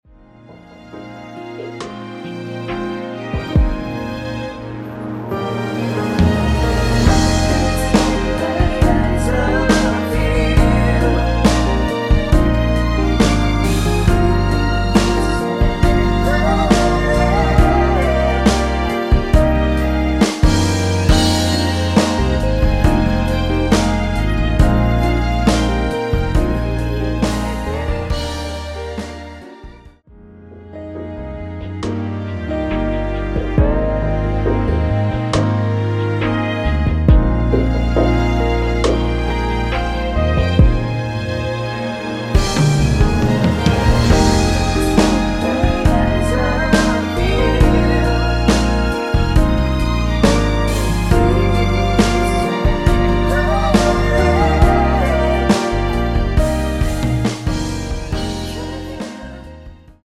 원키 코러스 포함된 MR입니다.(미리듣기 확인)
Eb
앞부분30초, 뒷부분30초씩 편집해서 올려 드리고 있습니다.
중간에 음이 끈어지고 다시 나오는 이유는